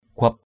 /ɡ͡ɣʊap/ (đg.) nhân đôi, nhân lên. multiply.